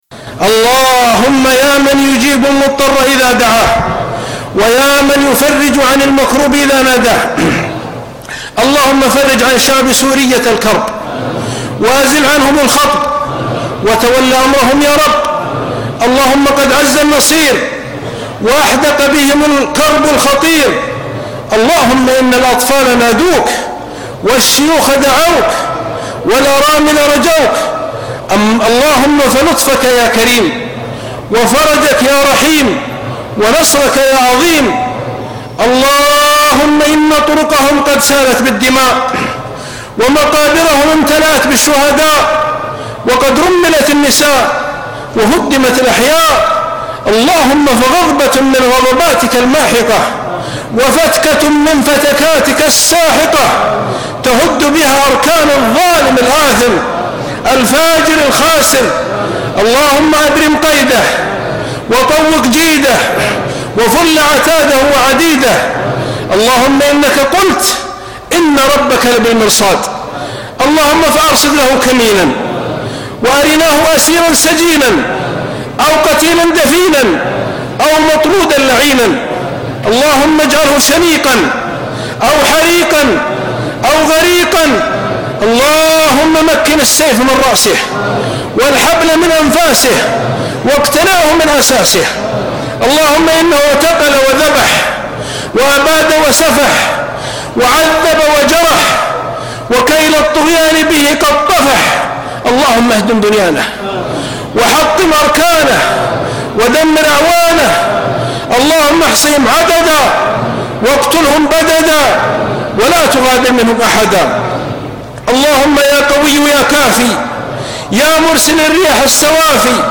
Description: - Ø¯Ø¹Ø§Ø¡ Ù„Ø§Ù‡Ù„ Ø³ÙˆØ±ÙŠØ§ Tags: Ø§Ù„Ø¯Ø¹Ø§Ø¡ ( 0 ) ( 0 Votes ) 1 2 3 4 5 Bookmark Blink Digg Furl Deli Google Report Abuse Add Favorites Download audio